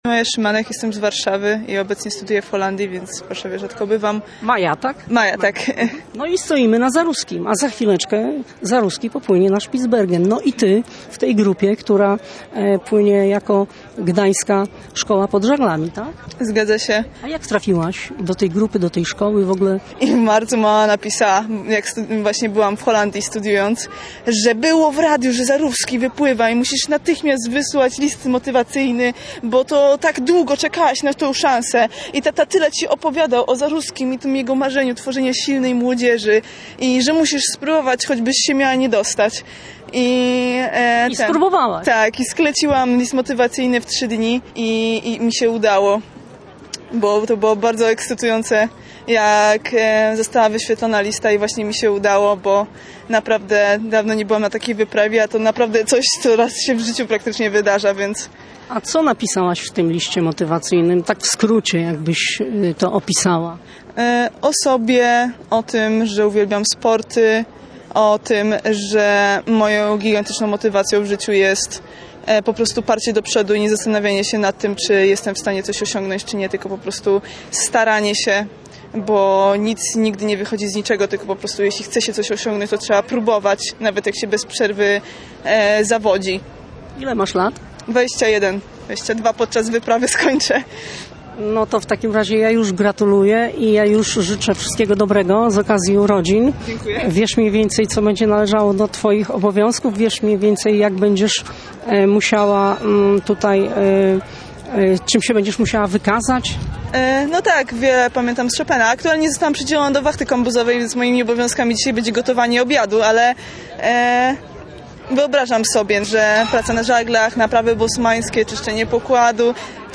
Zaruski_rozmowa_z_uczestnikami_Gdanskiej_Szkoly_pod_Zaglami.mp3